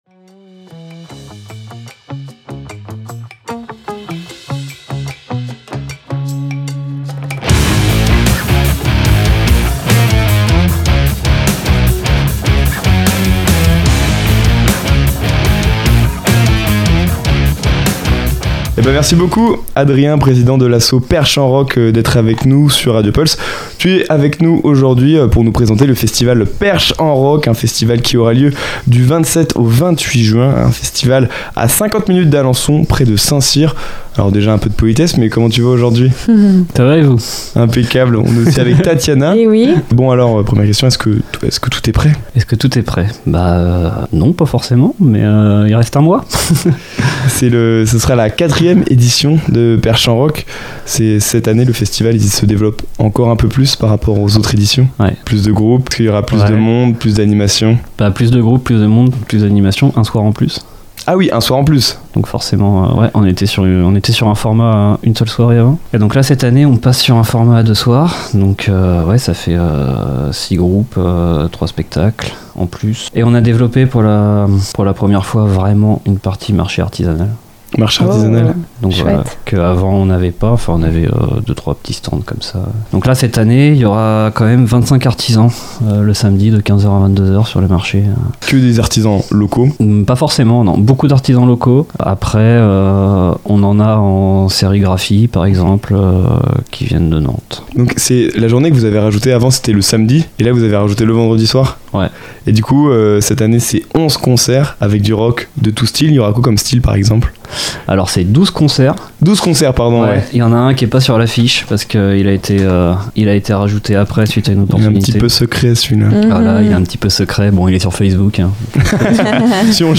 Il est venu dans les studios de Radio Pulse nous parlait de la programmation du festival, ainsi que de son histoire. Pour en savoir plus sur cet rencontre n'hésitez pas à écouter jusqu'au bout l'interview, et pourquoi pas vous aussi, faire partie des festivaliers !